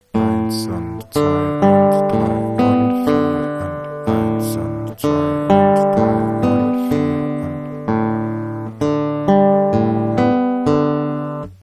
Der rechte Zeigefinger und Mittelfinger ist bei folgendem Stück für g- und h-Saite zuständig. Achtet auch auf den Rhythmus (Viertel - Achtel):